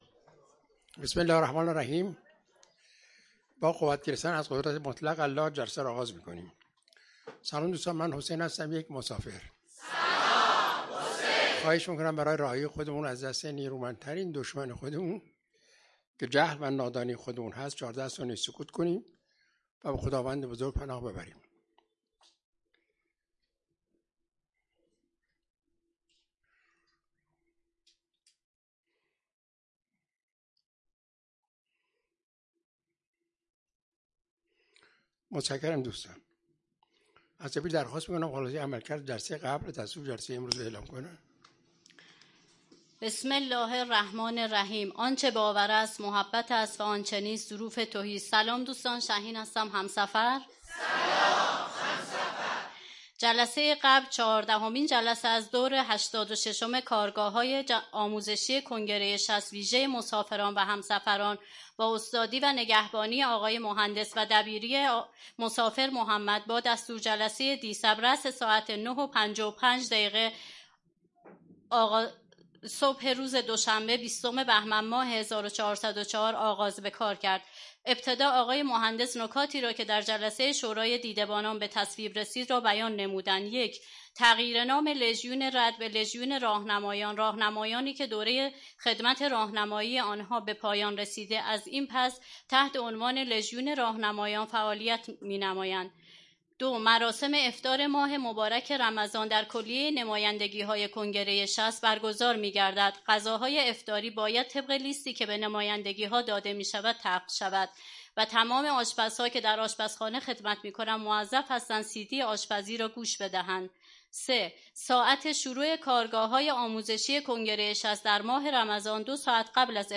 کارگاه آموزشی جهان‌بینی؛ تحقیقات کنگره 60 ، مقالات و کنفرانس ها
اولین جلسه از دوره هشتاد و هفتم کارگاه‌های آموزشی کنگره ۶۰